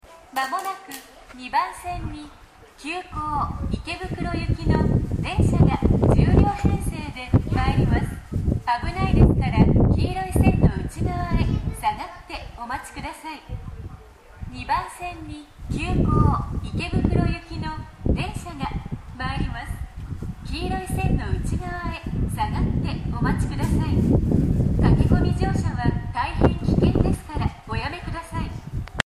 ２番線接近放送  途中切りです。
接近放送は急行　池袋行き放送です。